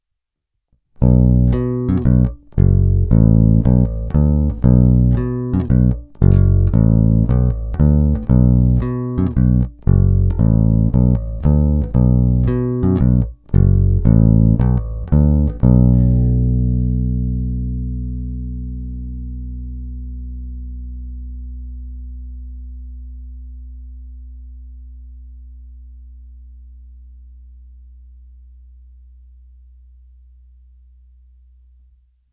Obecně zvuk této baskytary má ostřejší zvuk s kovovým nádechem, proto jsem i ve všech navazujících ukázkách stáhnul cca o 1/3 tónové clony obou snímačů.
Není-li uvedeno jinak, následující nahrávky jsou provedeny rovnou do zvukové karty, jen normalizovány, jinak ponechány bez úprav.
Snímač u krku